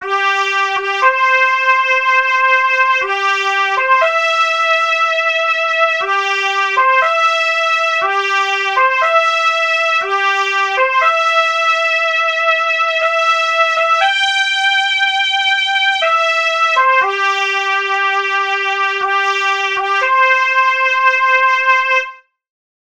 Oddílové signály
na trubku nás provázely celým táborem.